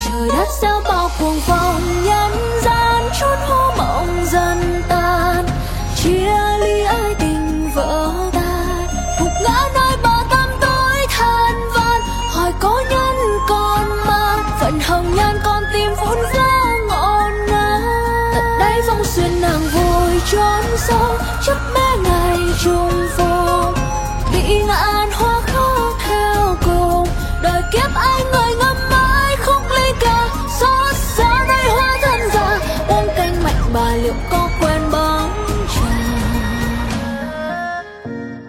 Nghe - Download (Tại đây) Thể loại: Nhạc Trẻ.